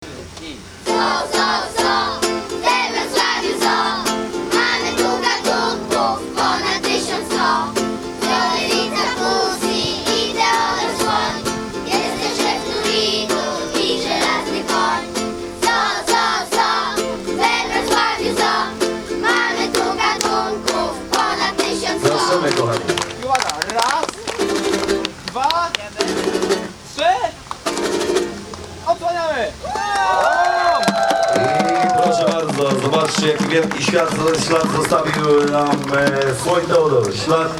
Dzieci uczestniczące w półkoloniach organizowanych przez zoo ułożyły na uroczystość otwarcia Alei Gwiazd specjalną piosenkę.